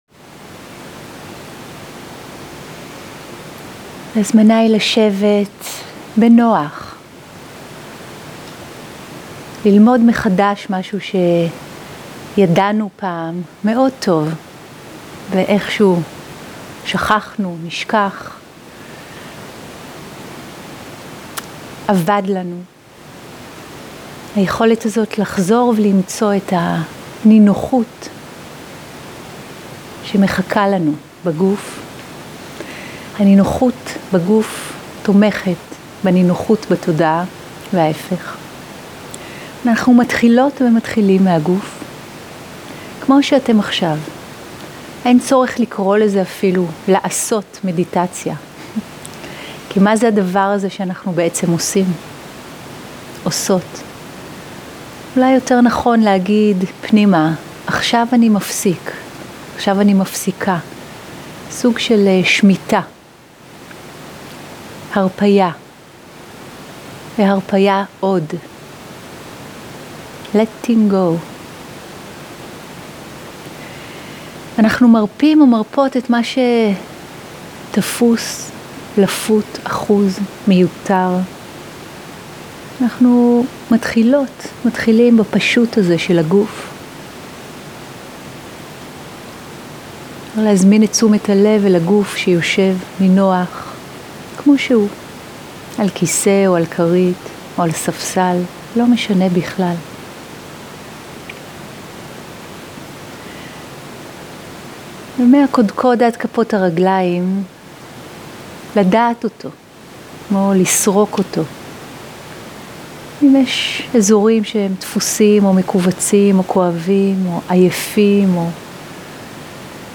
סוג ההקלטה: שיחת הנחיות למדיטציה
עברית איכות ההקלטה: איכות גבוהה מידע נוסף אודות ההקלטה